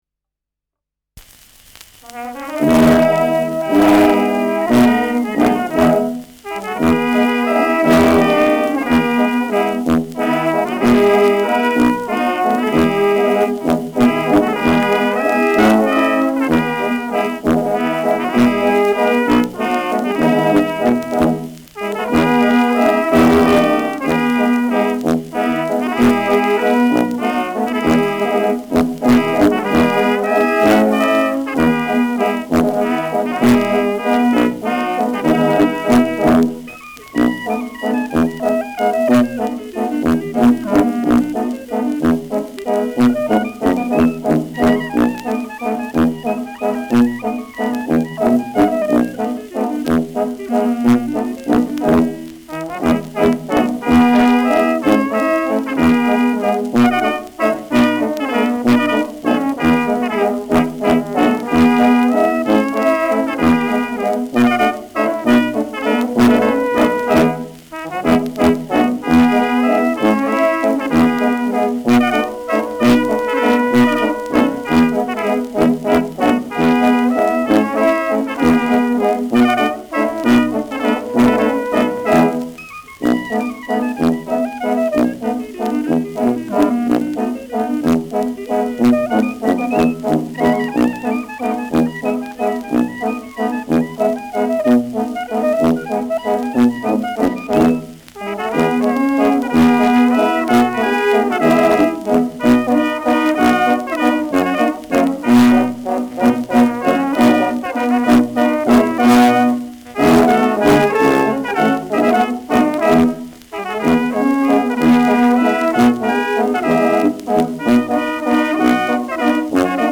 Schellackplatte
Dachauer Bauernkapelle (Interpretation)